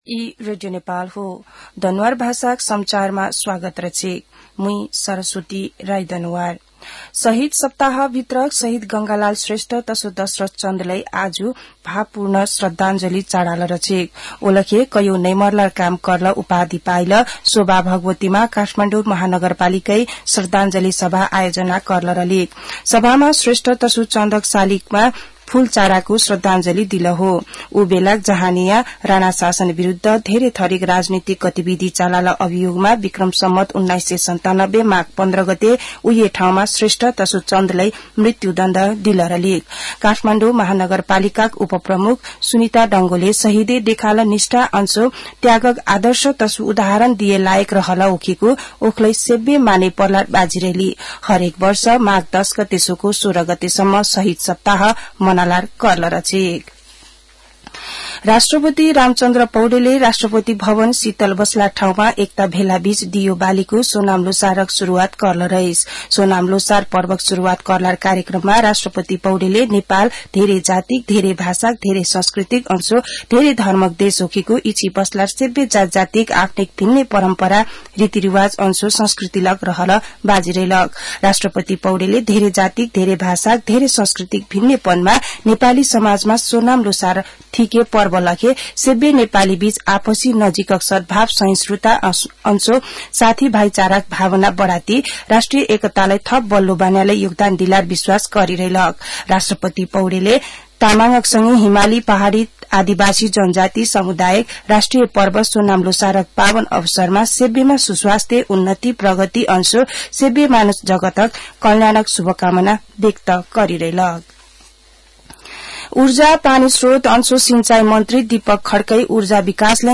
दनुवार भाषामा समाचार : १६ माघ , २०८१